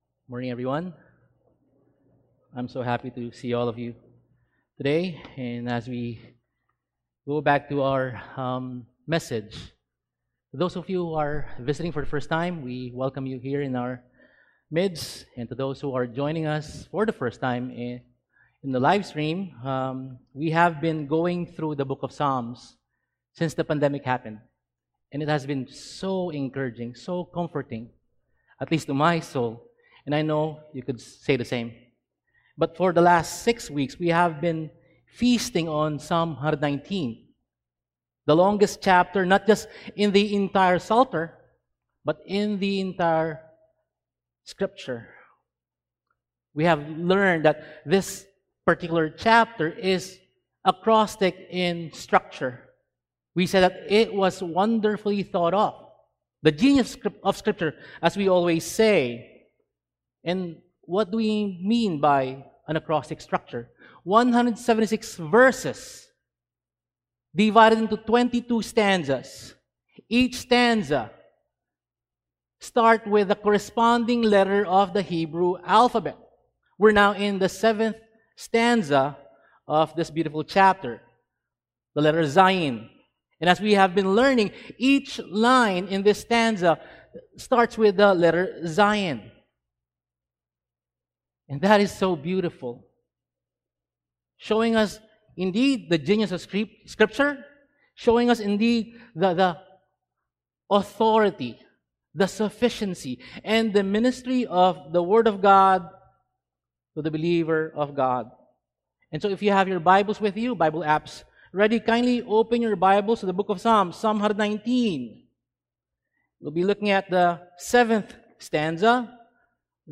Service: Sunday Sermon